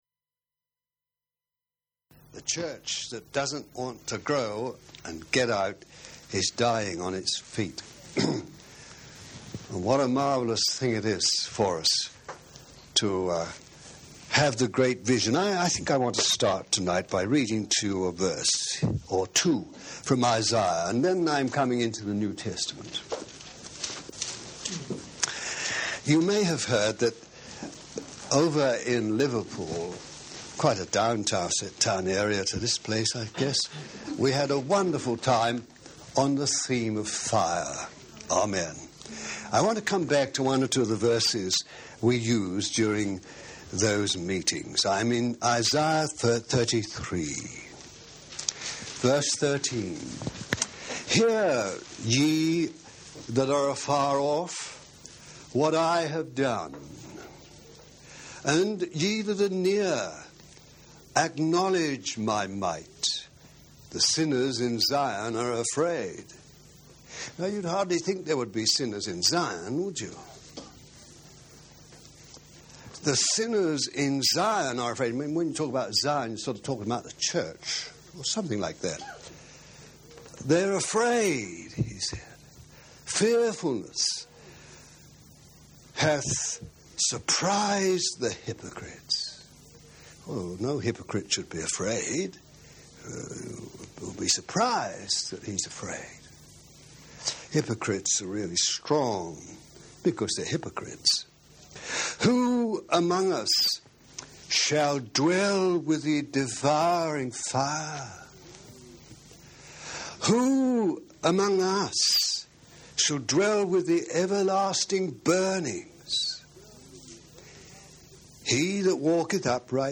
Message: “Everlasting Burnings
over a period of 4 years at Rora House CF, Devon; Devonshire Rd CF Liverpool; the Longcroft CF, Wirral